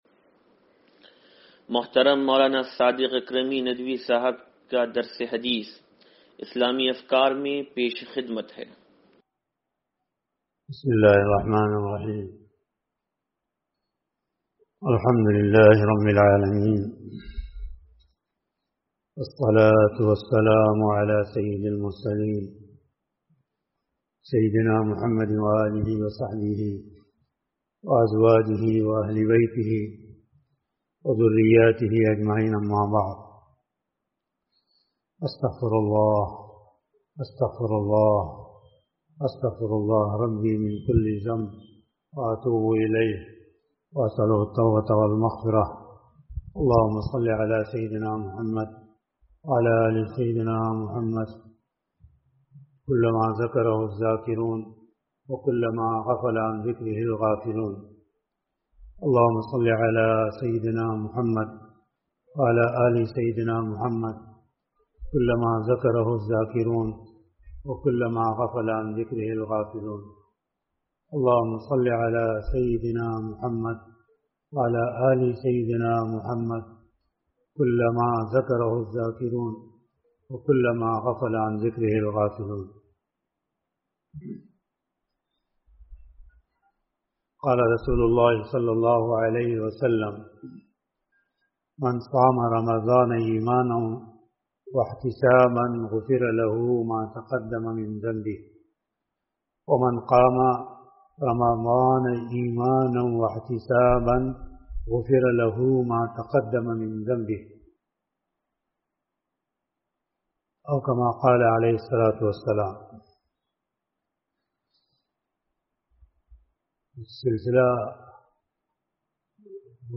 درس حدیث نمبر 0760
(سلطانی مسجد)